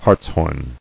[harts·horn]